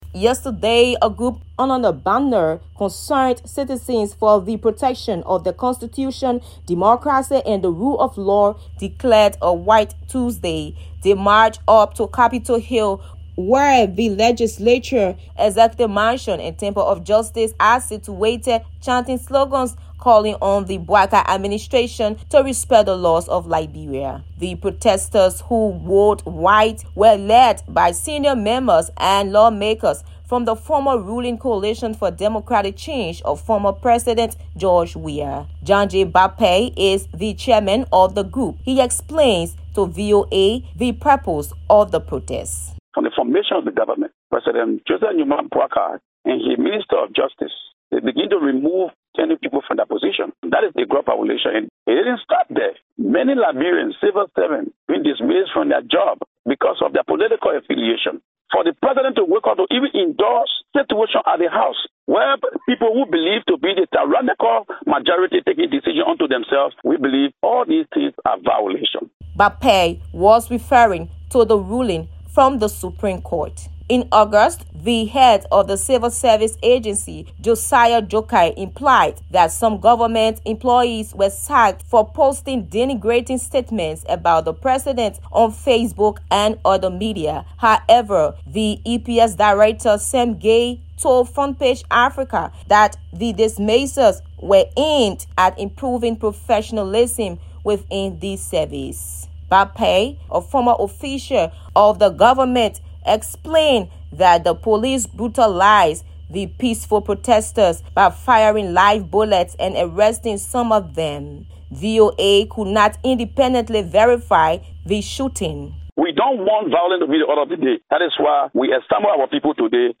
Daybreak Africa is a 30-minute breakfast show looks at the latest developments on the continent and provides in-depth interviews, and reports from VOA correspondents.